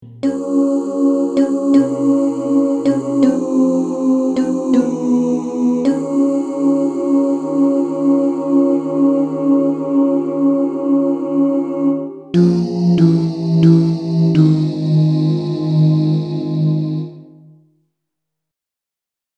Key written in: F Minor
Type: Other mixed
Comments: Take this at a nice easy ballad tempo.